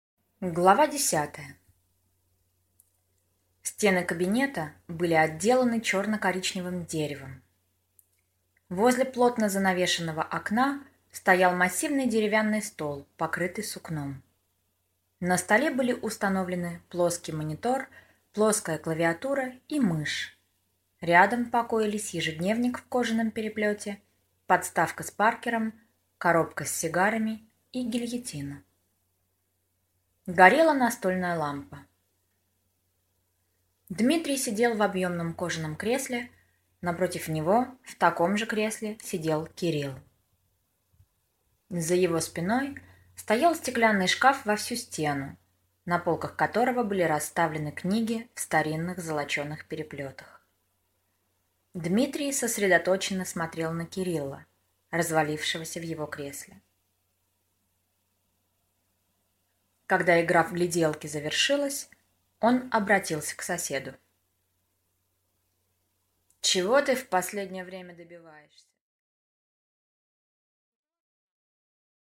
Аудиокнига Зяблик | Библиотека аудиокниг